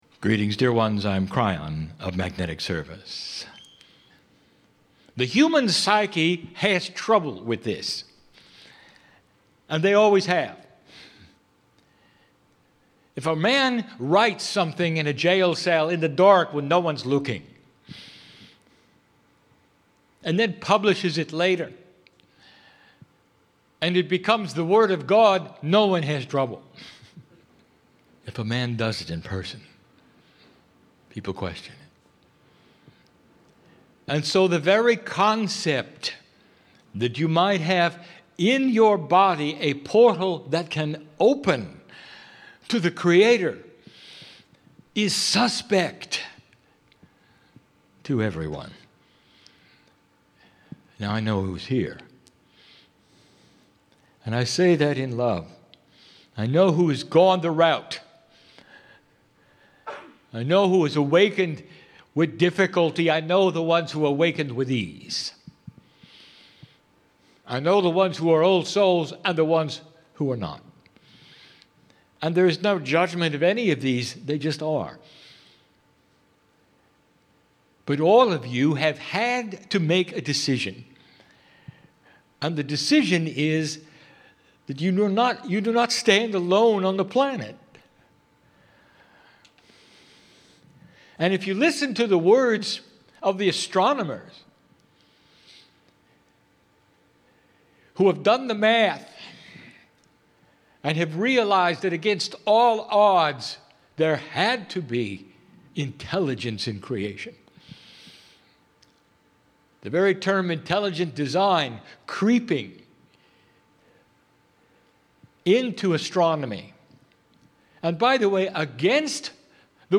2013 "THE ELUSIVE AKASH" Live Channelling
Mini Channelling